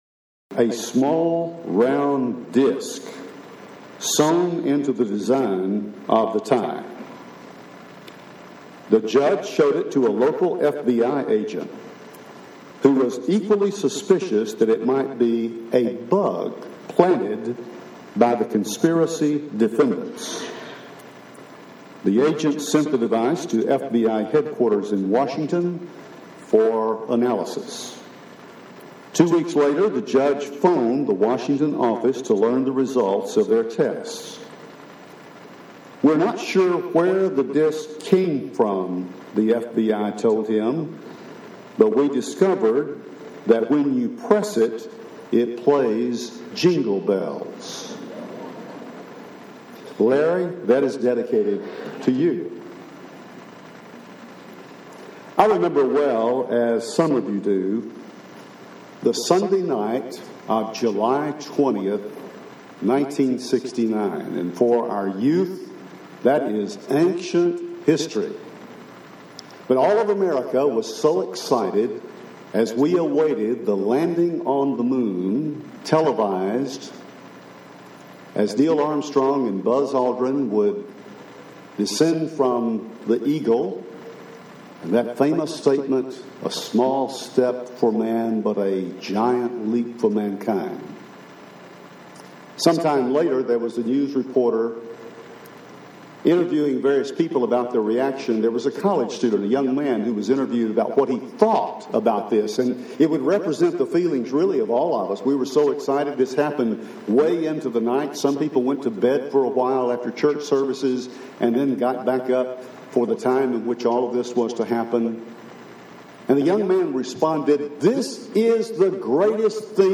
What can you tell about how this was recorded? And the Word Became Flesh – Henderson, TN Church of Christ